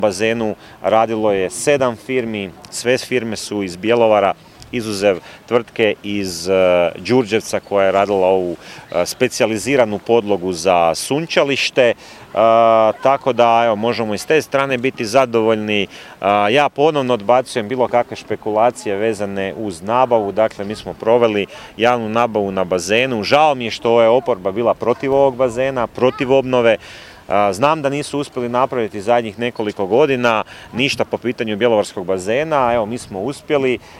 Bazeni su lijepi i čisti, a prigodom otvorenja gradonačelnik Dario Hrebak je rekao: